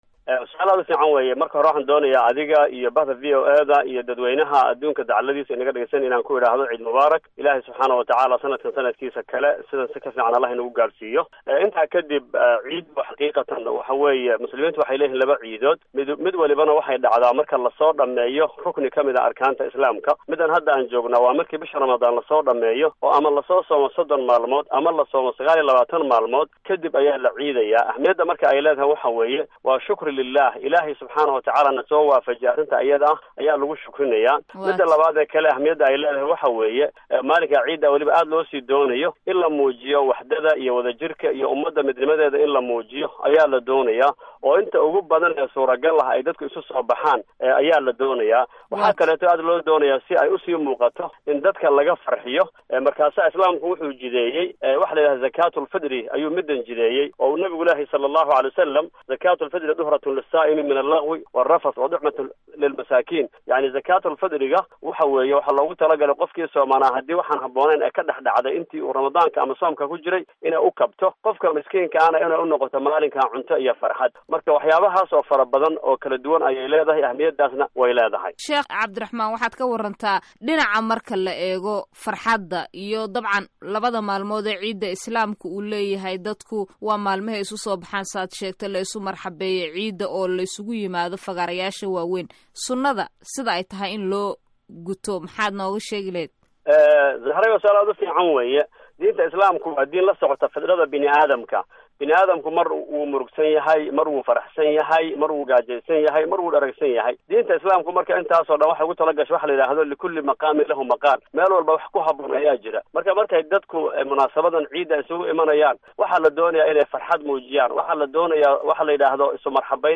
Waraysiga Ciidda